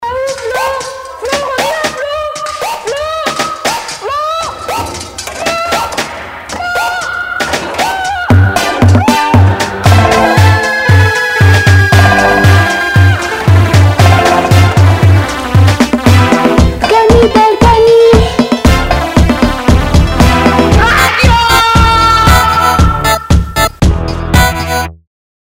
JINGLES